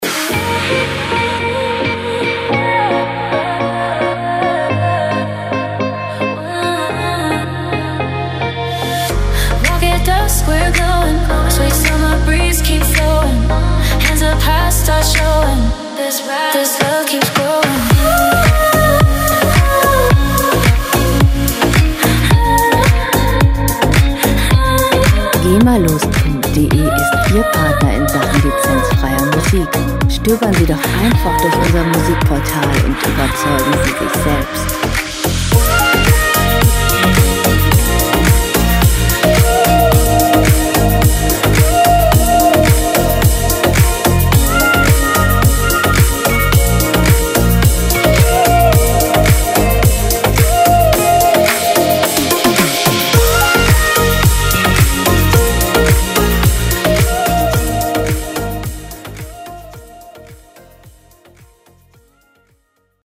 diese Tropical House Track
• Tropical House